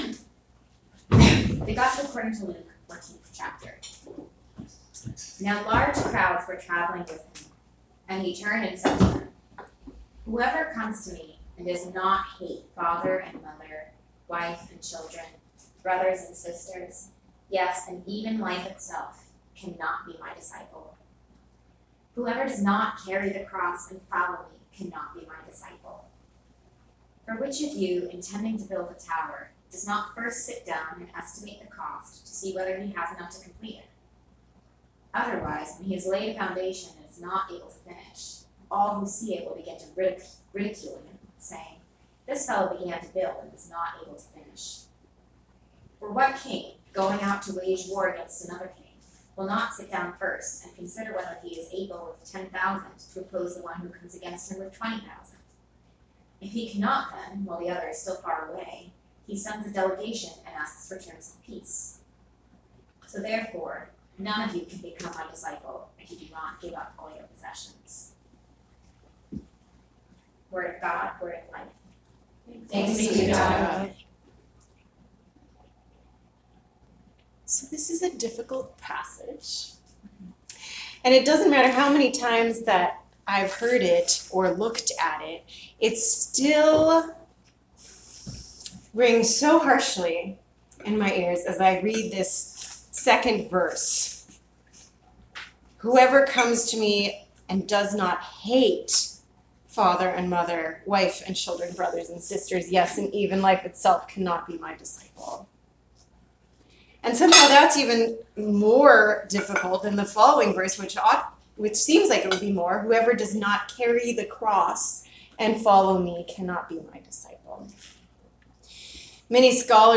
September 9, 2019 Sermon